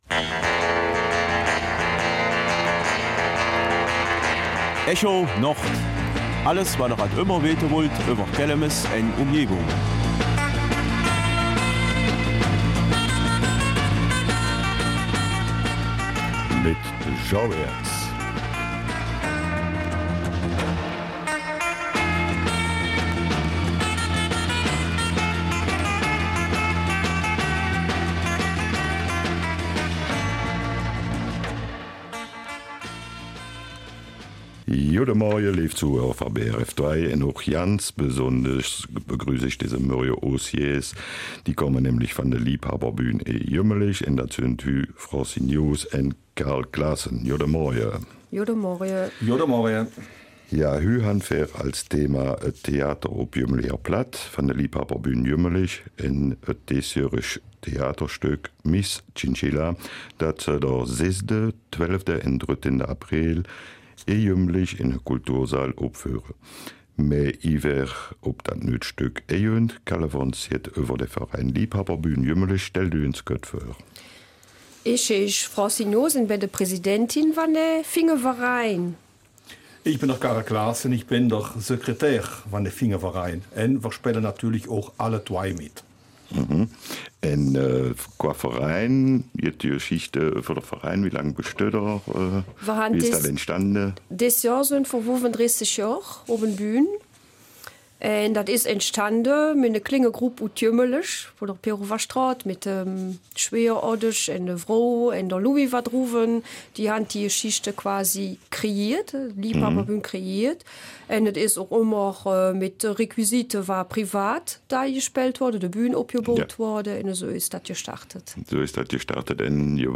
Die Studiogäste